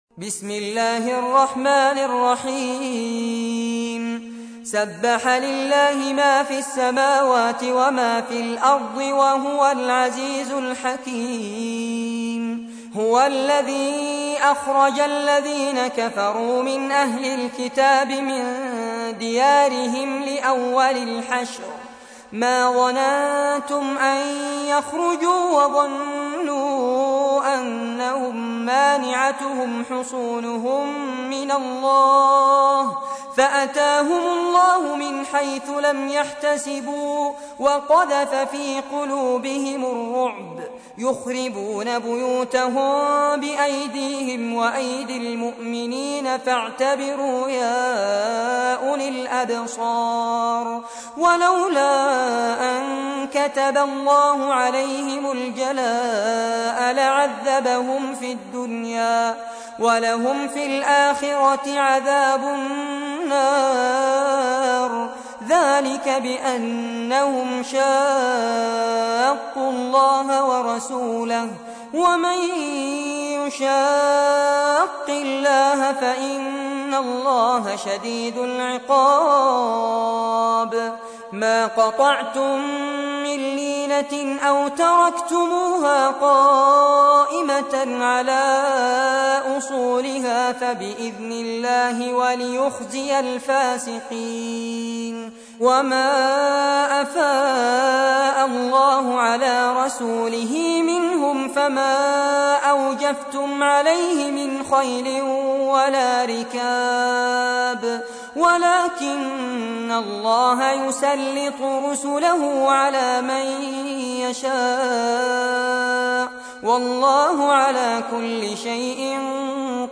تحميل : 59. سورة الحشر / القارئ فارس عباد / القرآن الكريم / موقع يا حسين